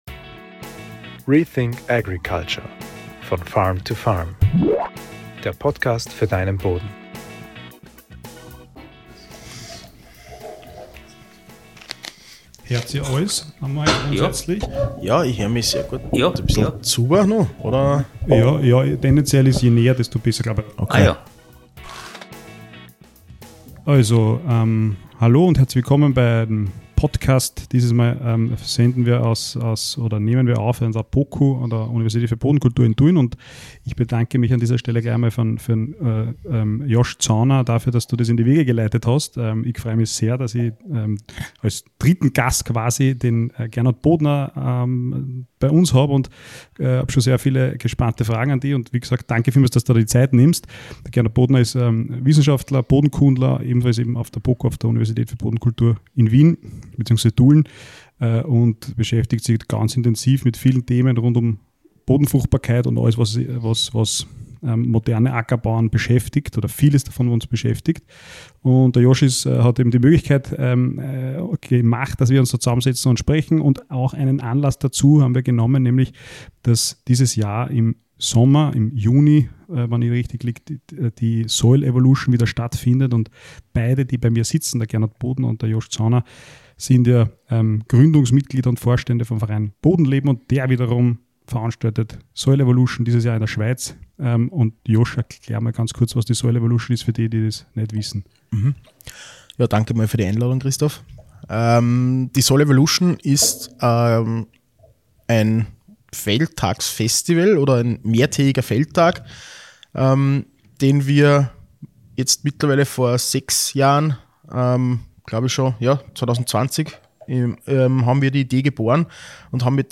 Wir sprechen daher über die wichtigsten Themen der Bodenfruchtbarkeit aus Sicht von Forschung UND Praxis. Diesen Podcast haben wir übrigens als Video-Podcast aufgenommen.